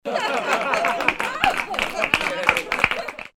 applause